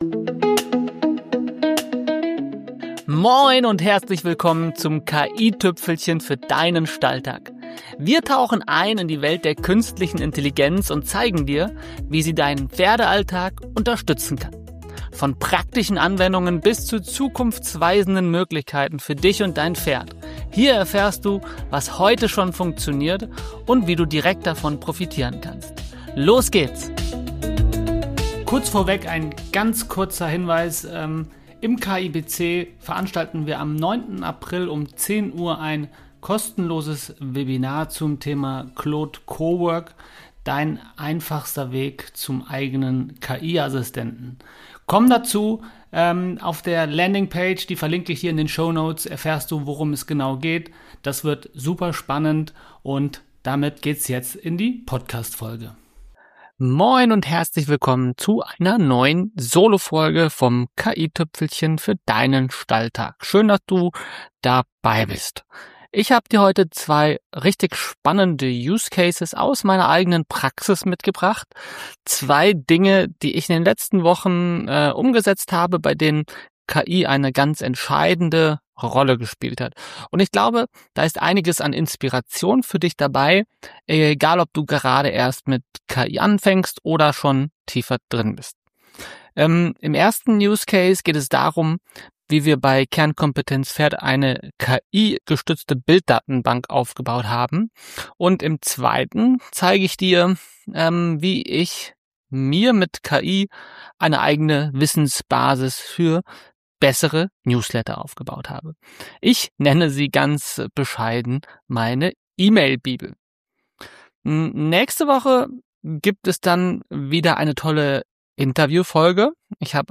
In dieser Solo-Folge teile ich zwei Use Cases aus meiner eigenen Praxis mit dir, bei denen KI eine entscheidende Rolle spielt. Keine Theorie, keine Tool-Vergleiche – sondern echte Workflows, die bei mir jetzt im Einsatz sind.